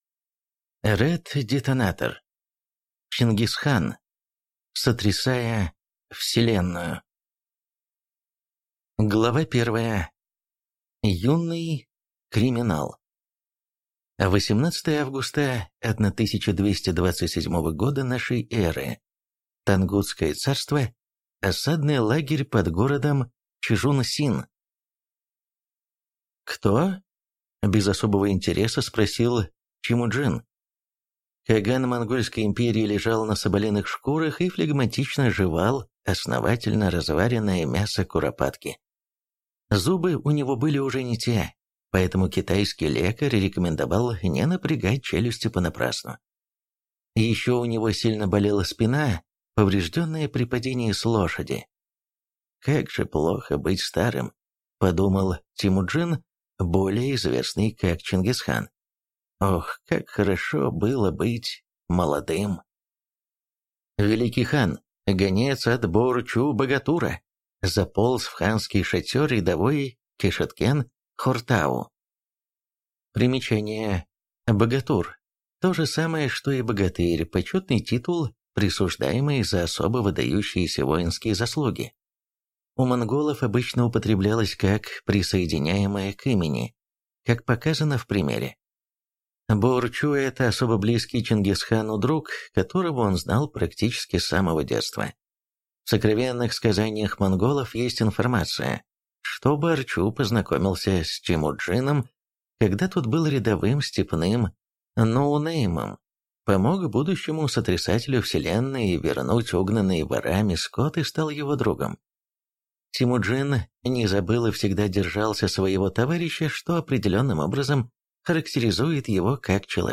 Аудиокнига Первая книга в серии «Сотрясатель Вселенной».